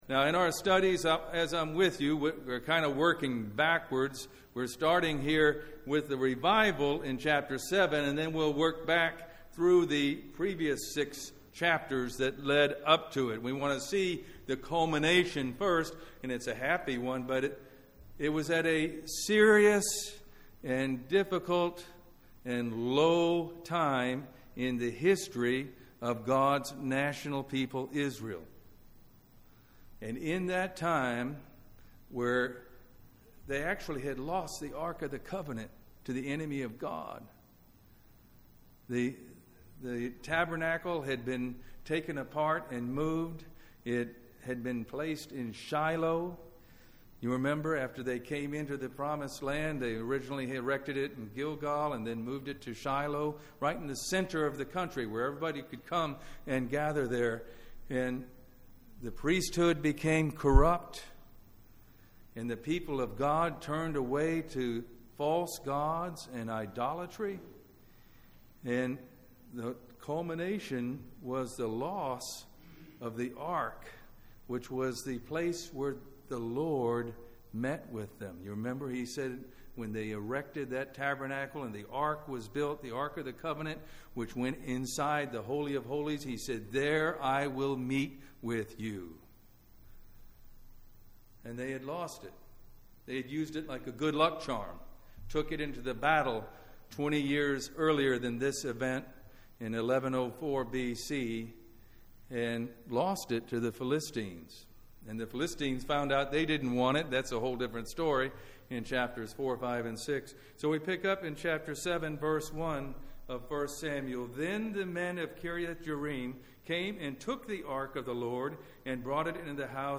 1 Samuel Service Type: Family Bible Hour Bible Text